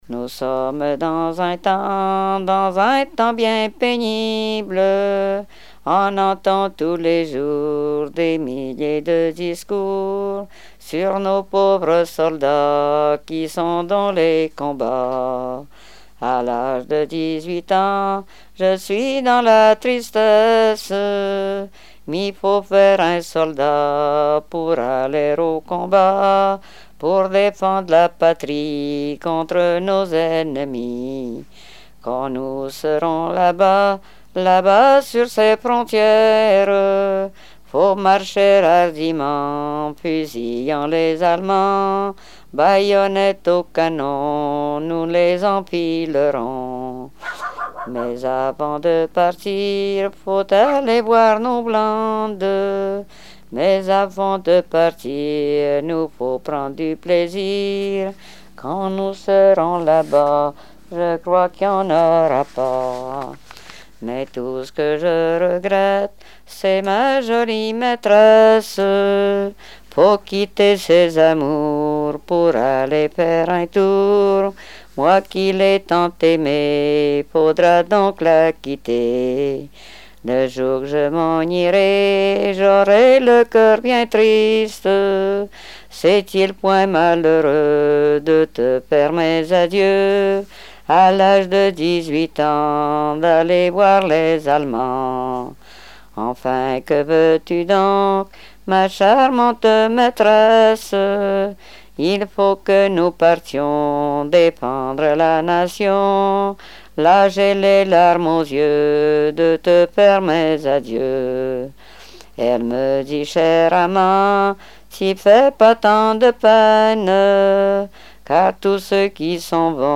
Notre-Dame-de-Monts
Répertoire de chansons traditionnelles et populaires
Pièce musicale inédite